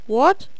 horseman_select1.wav